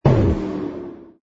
engine_ku_fighter_kill.wav